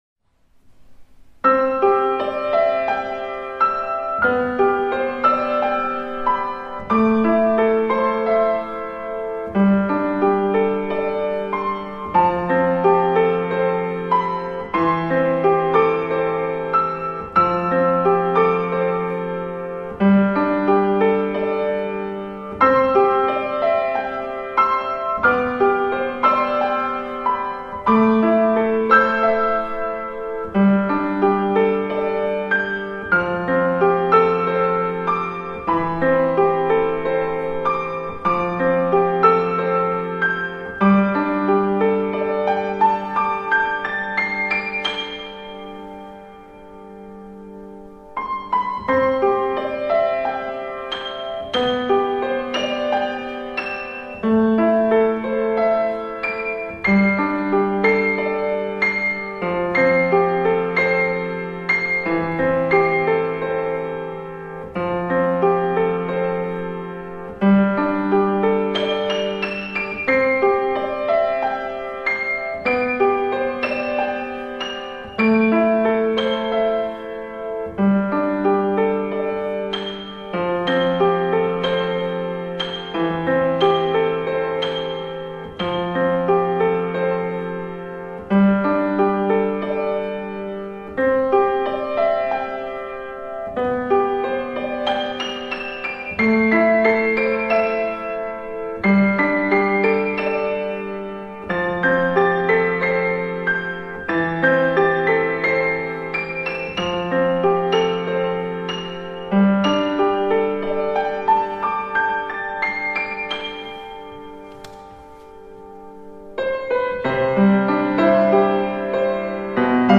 スタインウェイD274でオリジナル曲を2曲ピアノ演奏
クラシックチック？というか現代音楽風バラード？
普段より1オクターブ高いバージョンで演奏させていただきましたが、
鍵盤を弾くと音が軽やかに出る！響きもす～っと良い感じです、
コンサートホールで弾く解放感というか爽快感と言いますか、コンサートホールの響きも心地よく、音の抜けがいいというか、
そのためか、ちょっとやる気というか気持ちが前に出すぎてテンポが速くなってしまいましたが、
ピアノ曲永遠の3分を再生して聴いていると、1分51秒あたりでガタッ！と音が聞こえますが、